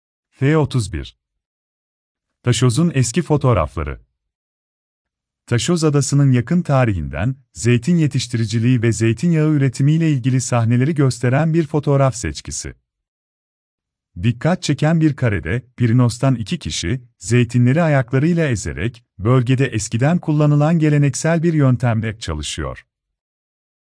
Sesli rehberli tur